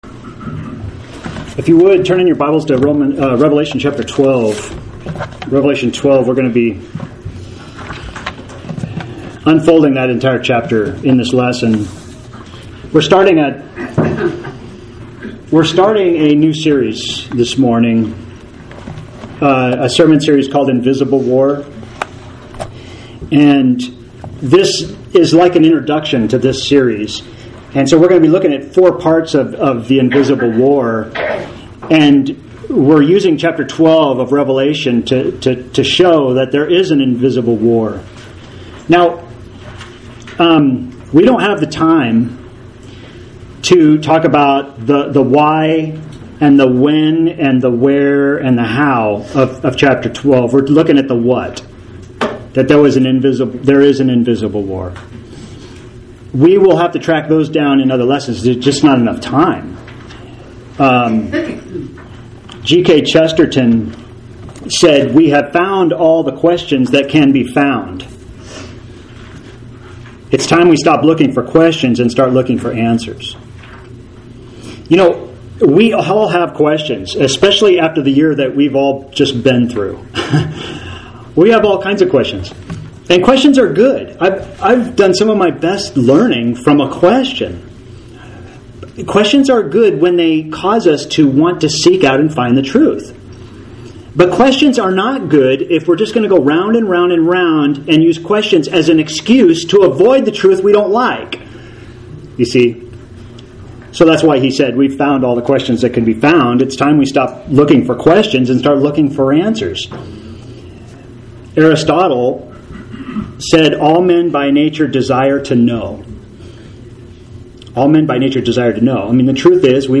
Sermon Series: The Invisible War. This is a new focus of study on the spiritual war for our minds and souls.